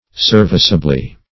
Serv"ice*a*bly, adv.